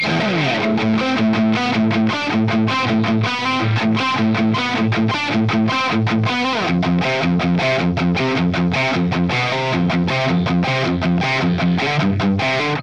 The M1 Epona pack includes captures ranging from clean to full metal hi gain and everything in between plus my personal YouTube IR that I use in my demos are also included.
Metal Riff
RAW AUDIO CLIPS ONLY, NO POST-PROCESSING EFFECTS